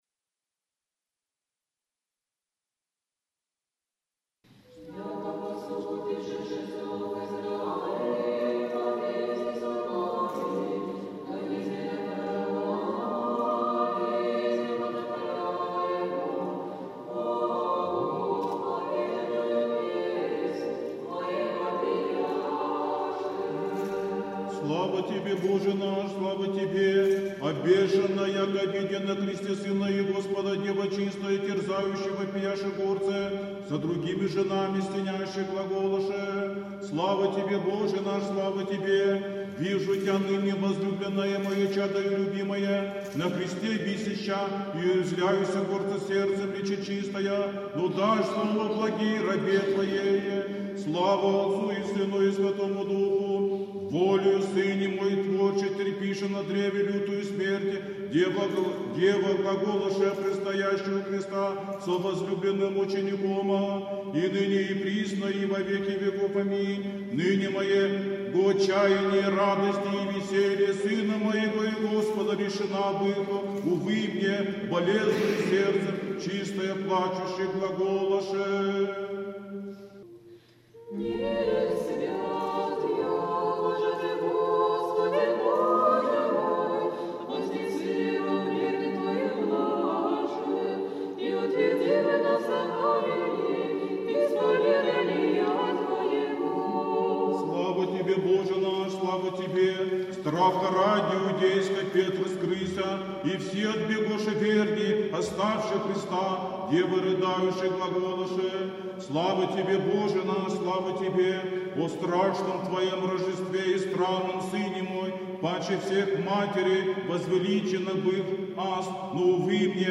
Великая Пятница | Свято-Троицкий Стефано-Махрищский монастырь
Канон на повечерии Канон на повечерии 25:24 Антифоны Антифон 4.